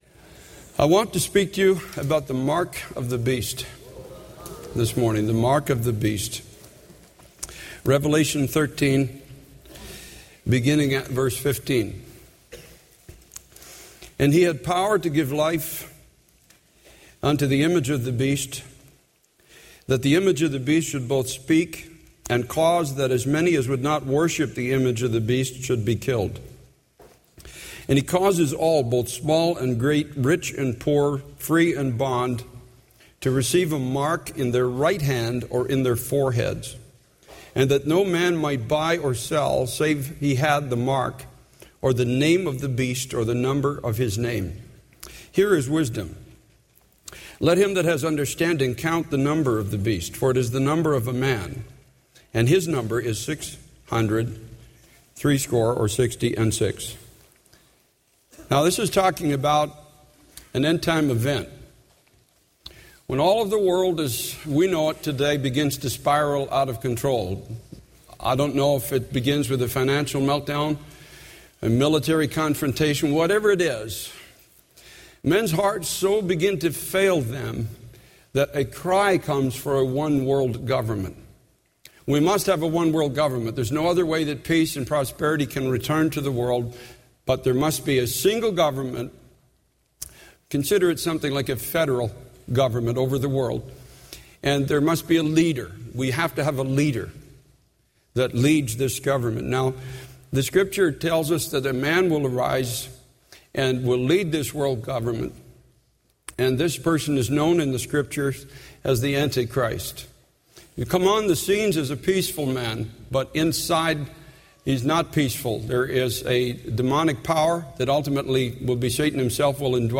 In this sermon, the preacher discusses the concept of the mark of the beast mentioned in the book of Revelation. He explains that this mark will be given by the Antichrist, who will rise to power during an end-time event of global chaos. The mark will be required for people to participate in the economy, and those who refuse to receive it will face severe consequences, even death.